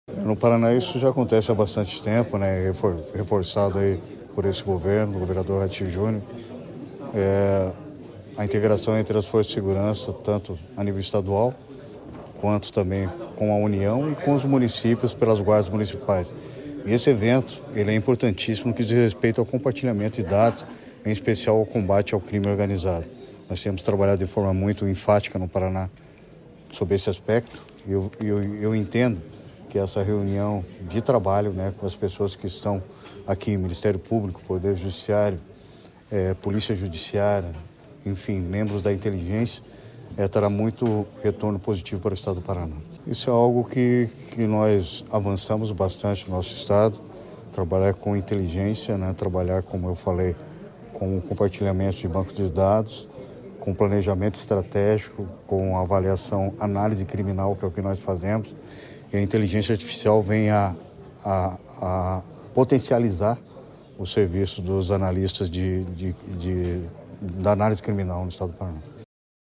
Sonora do secretário da Segurança Pública, Hudson Teixeira, sobre a realização do 3º Encontro Técnico da Renorcrim em Curitiba